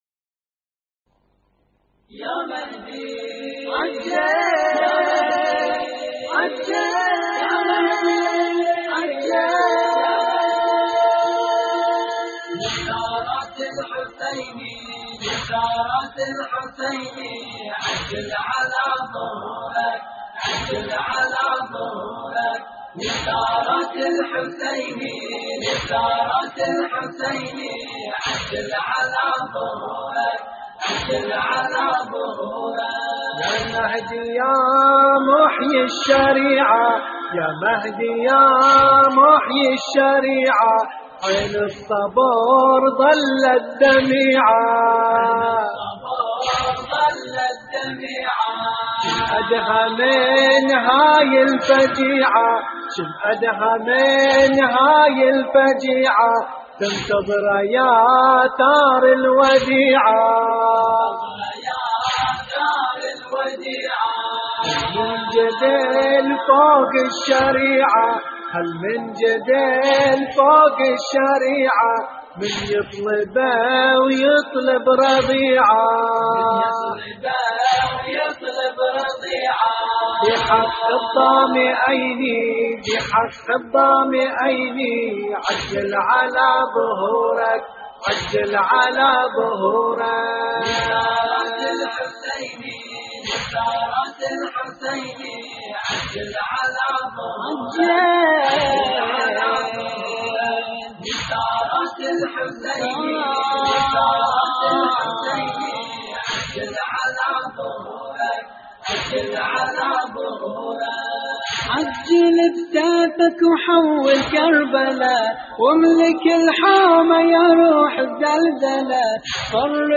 استديو «الظليمة»